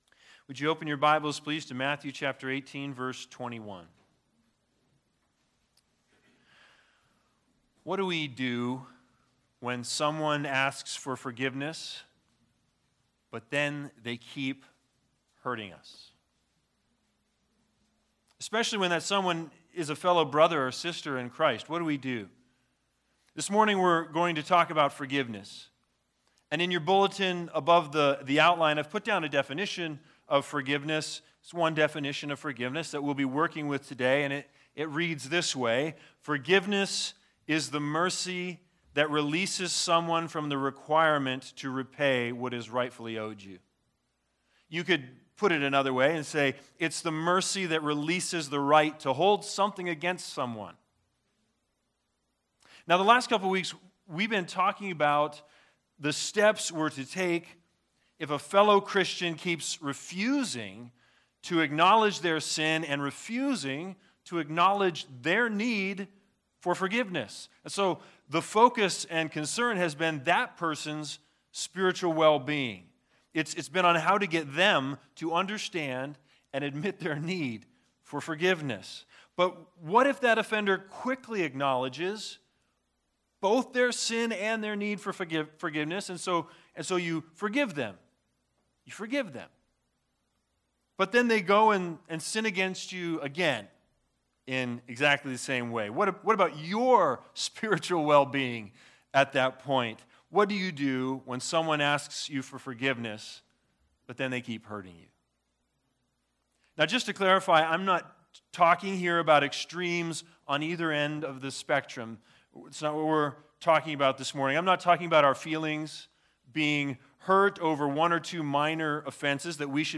Matthew 18:21-35 Service Type: Sunday Sermons The Big Idea